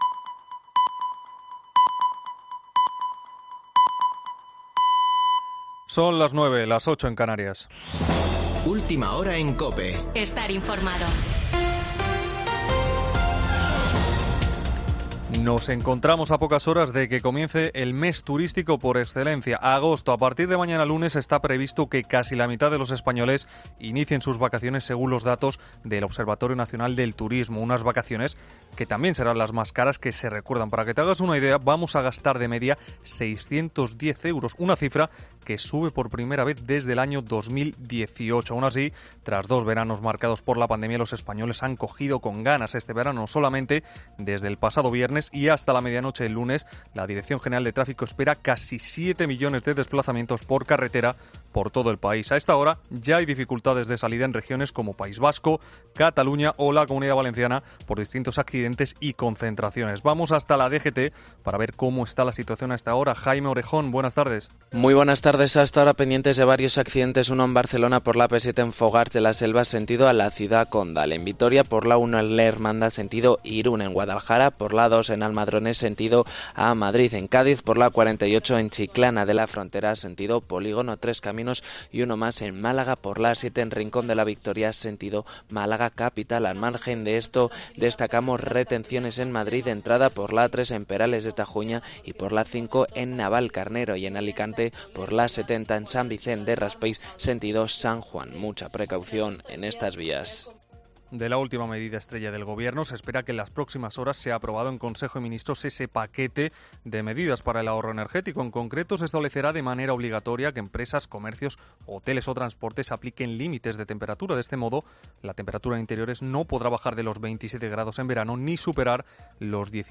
Boletín de noticias de COPE del 31 de julio de 2022 a las 21.00 horas